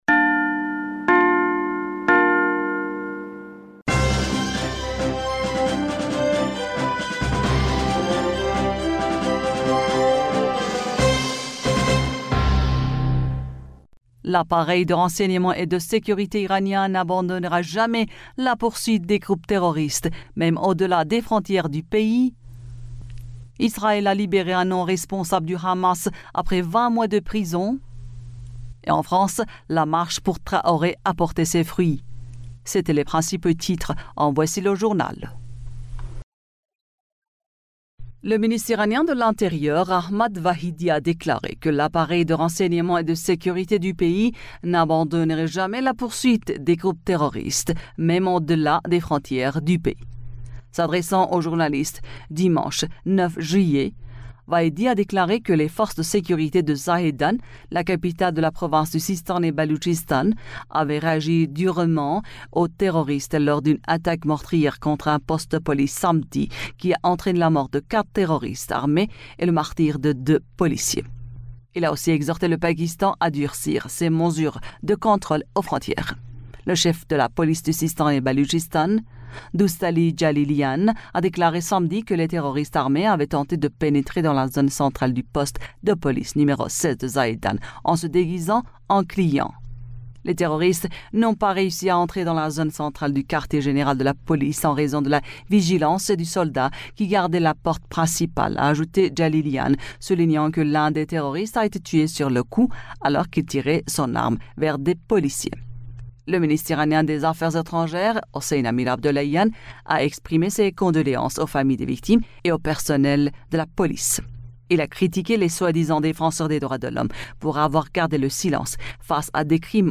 Bulletin d'information du 10 Juillet 2023